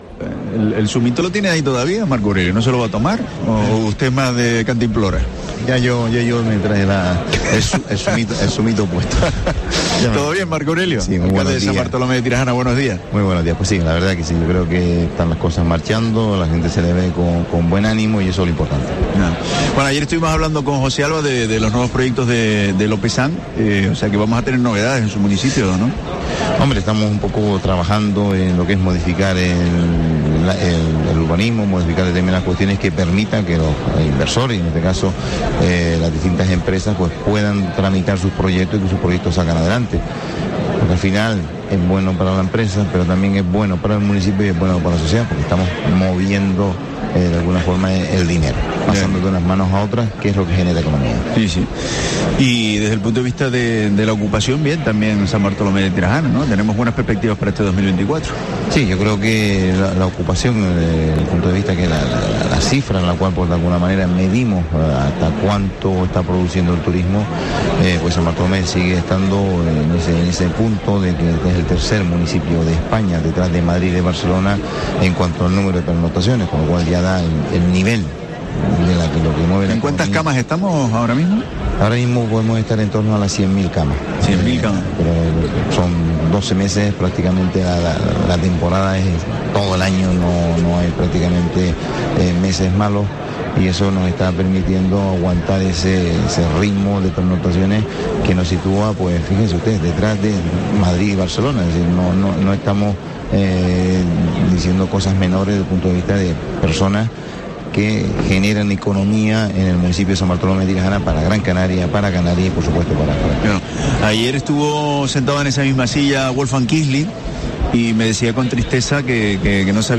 Entrevista a Marco Aurelio Pérez, alcalde de San Bartolomé de Tirajana en FITUR